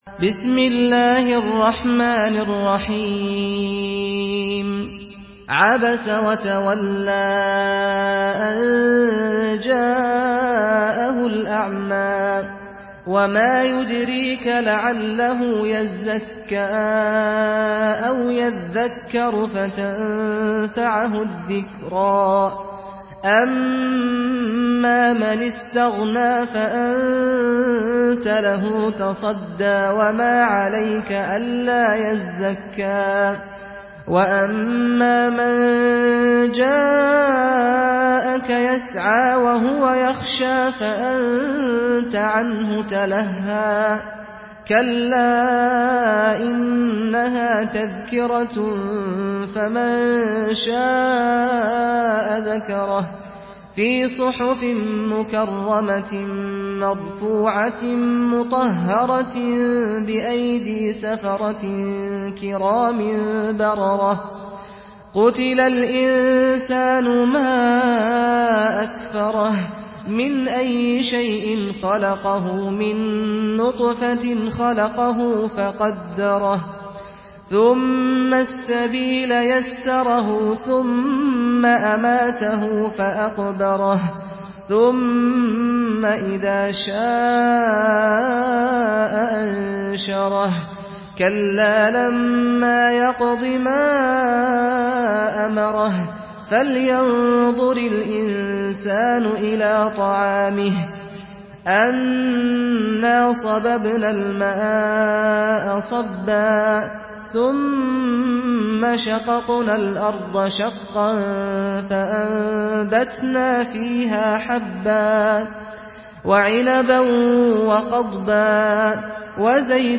Surah ‘আবাসা MP3 in the Voice of Saad Al-Ghamdi in Hafs Narration
Murattal Hafs An Asim